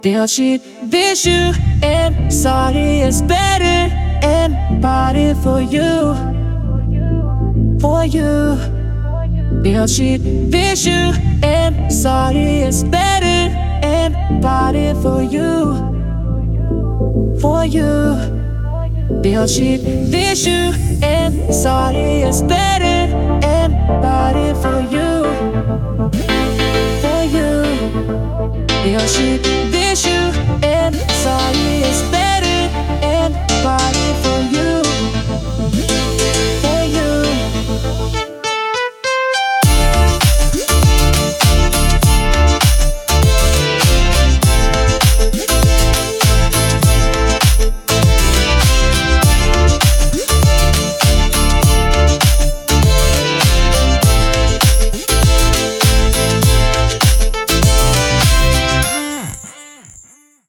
I got Suno to sing nonsense again. You can really tell how this generative AI works when it goes off the rails.